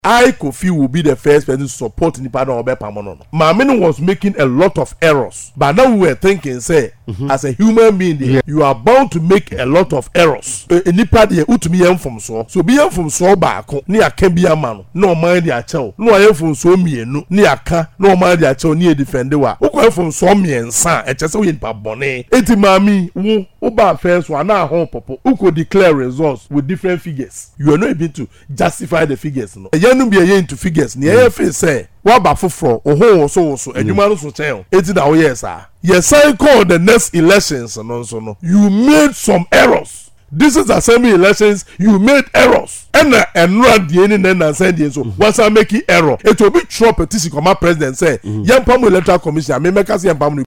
Speaking on Radio1’s morning show